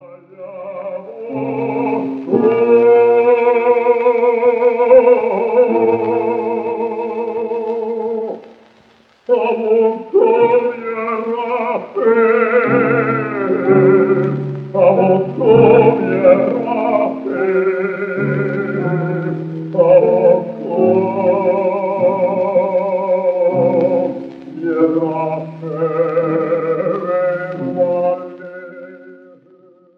Classical
Cantorial